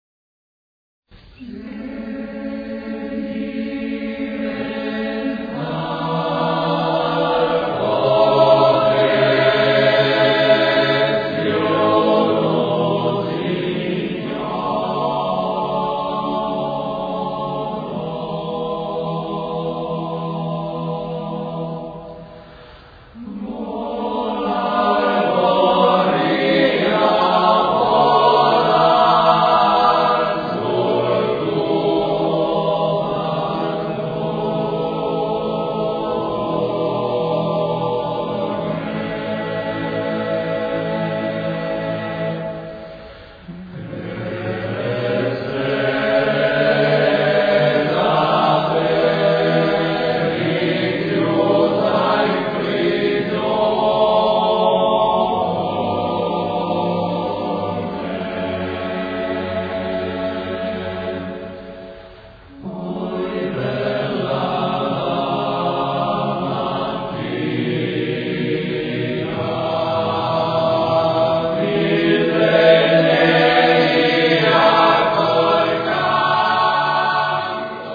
Ricerca, elaborazione, esecuzione di canti popolari emiliani
voci virili